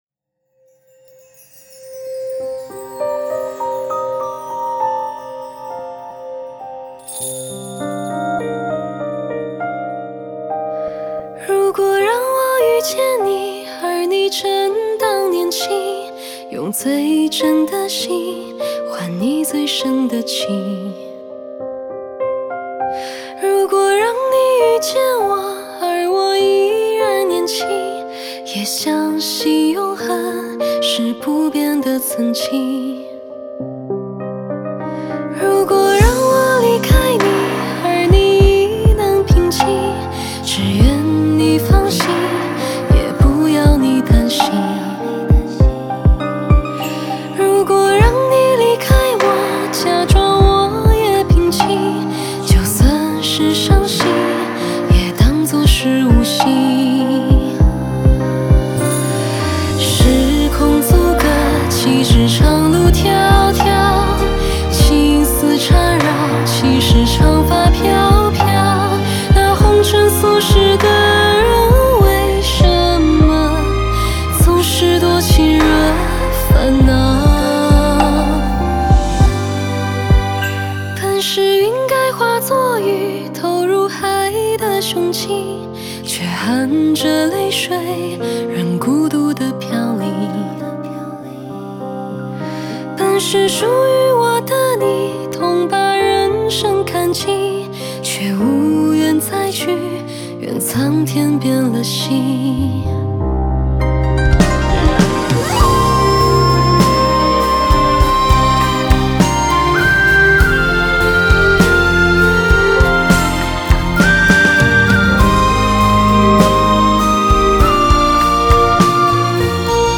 Ps：在线试听为压缩音质节选，体验无损音质请下载完整版 如果让我遇见你，而你正当年轻， 用最真的心，换你最深的情。